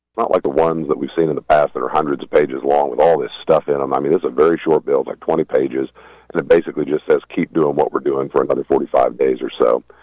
He had addressed the possibility during his monthly visit on KVOE’s Morning Show late last week.